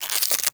ALIEN_Insect_11_mono.wav